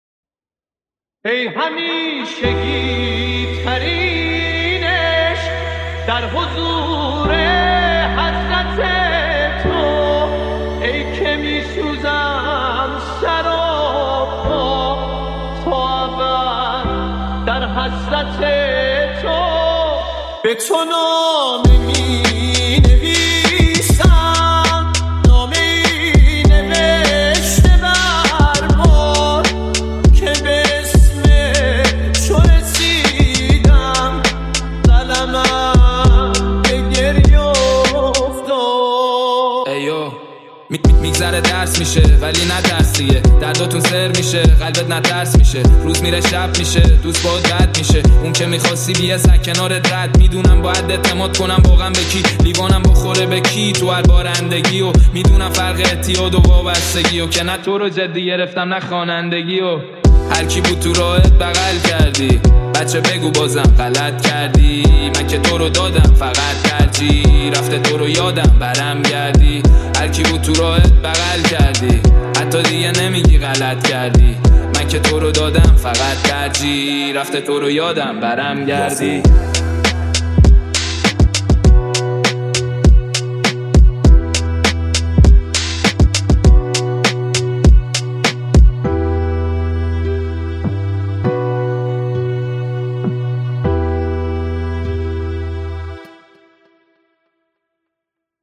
ژانر: ریمیکس